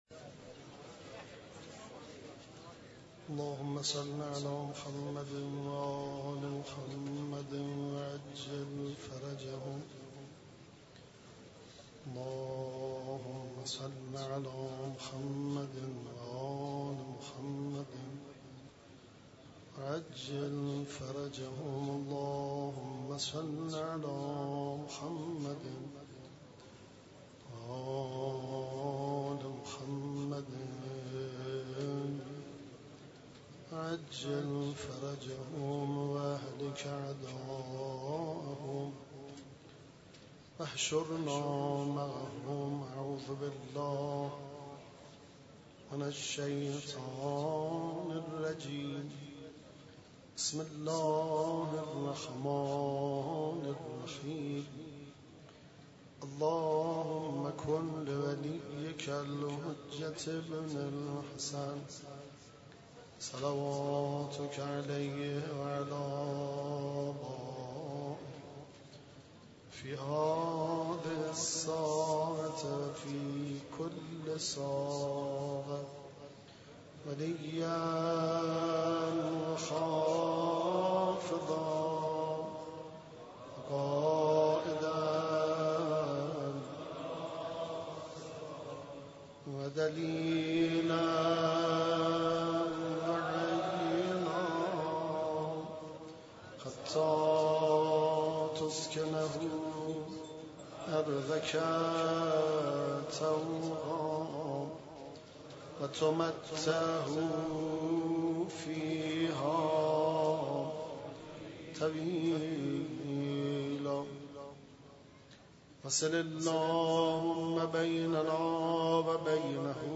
قرائت مناجات حضرت امیر (علیه السلام) ، روضه امام علی (علیه السلام)